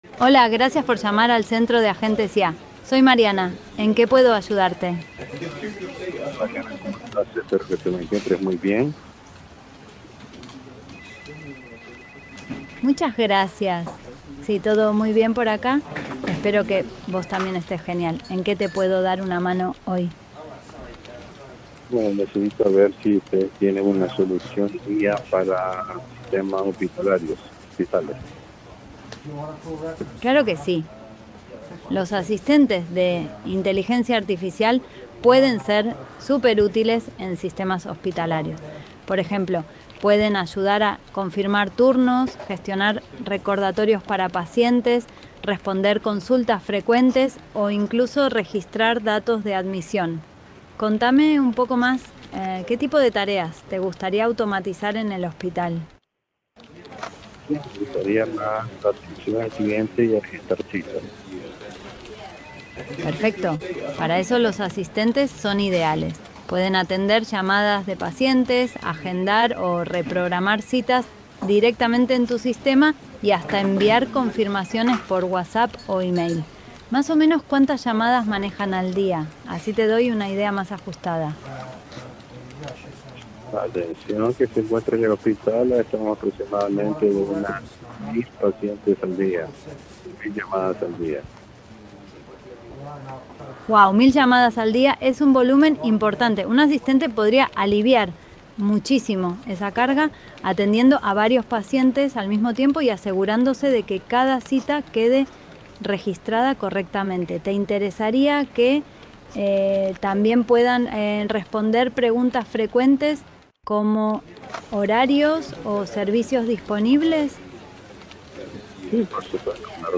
Voicebot – Gesher Ai
Un asistente telefónico con IA siempre disponible.